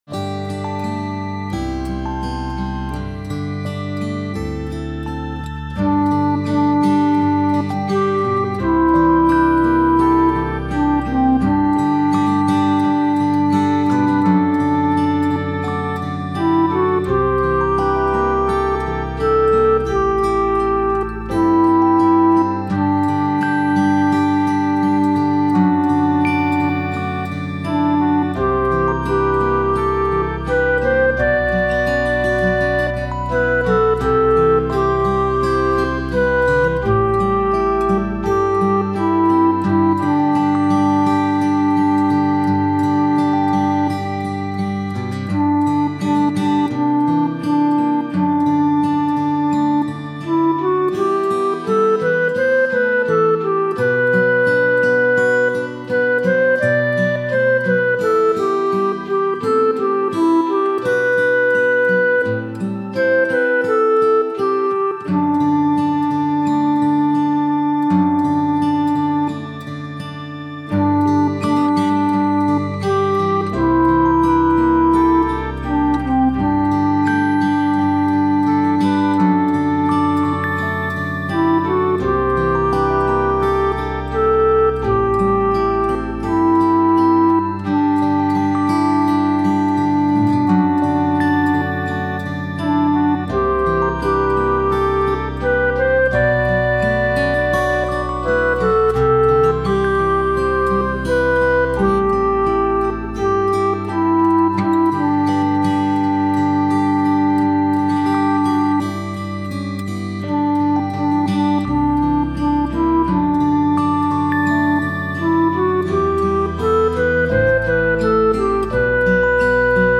There are subtle differences in the melody between the verses, which we have always completely ignored.